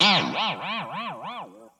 Boing (4).wav